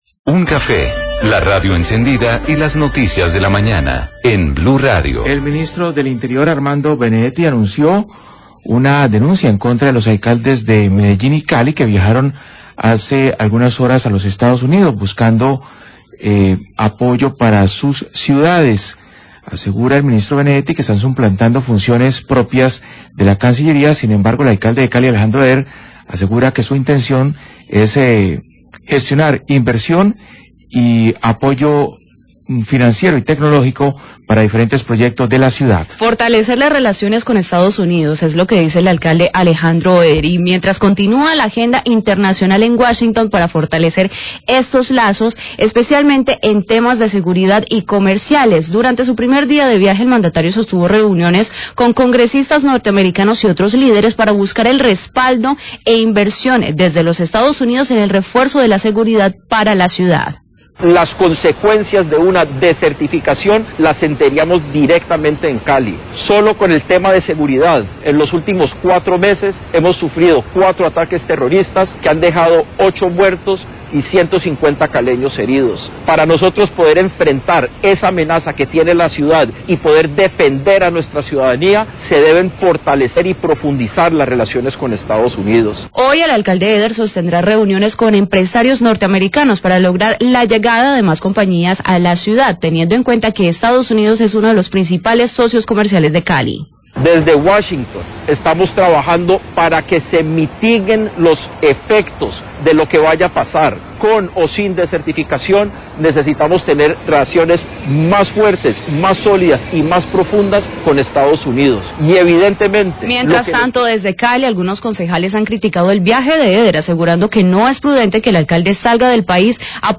Radio
Mininterior Armando bendetti anuncia denuncia contra los alcaldes Aejandro Eder y Federico Gutiérrez por usurpación de funciones del gobierno nacional por su viaje a EEUU. Habla el alcalde Eder y dice que las consecuencias de una descertificación las sentiría Cali.